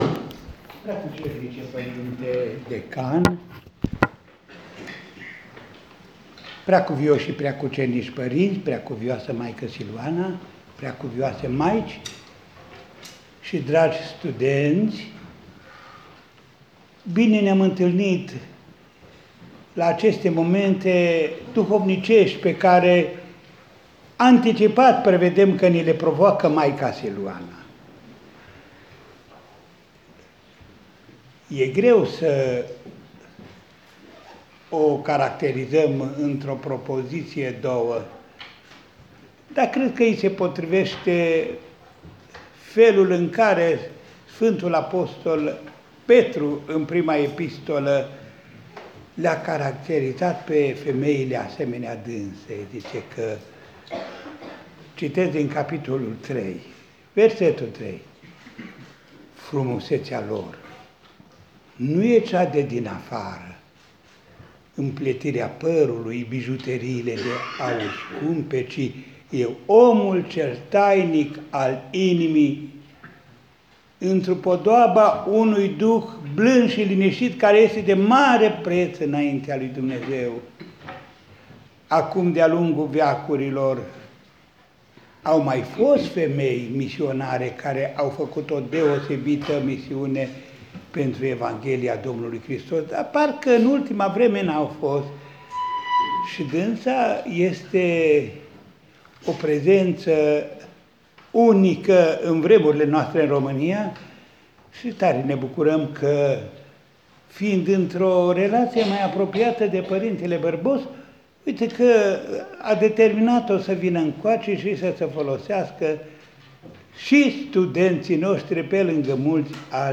Cu acest prilej, Înaltpreasfințitul Părinte Andrei, în deschiderea serii, a adresat un cuvânt duhovnicesc, a apreciat misiunea pe care maica o face în rândul tinerilor și i-a mulțumit pentru prezența sa la Cluj-Napoca.
Cuvânt-ÎPS-Andrei-1.m4a